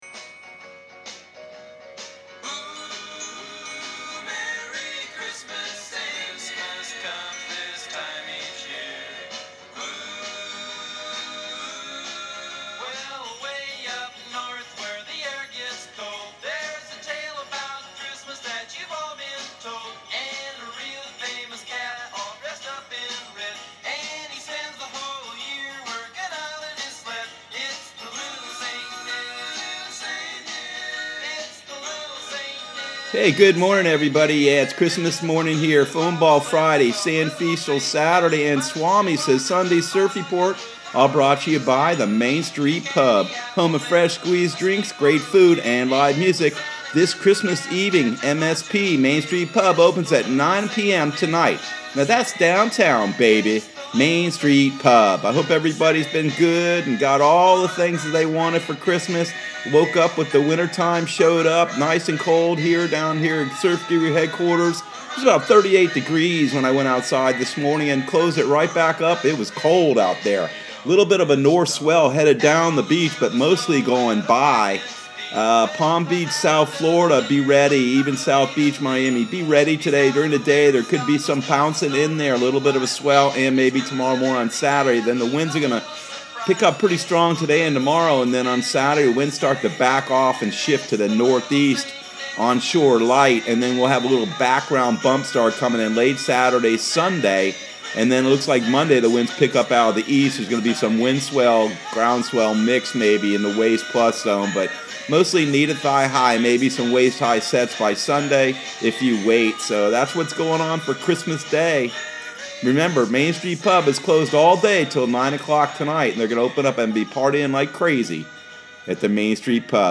Surf Guru Surf Report and Forecast 12/25/2020 Audio surf report and surf forecast on December 25 for Central Florida and the Southeast.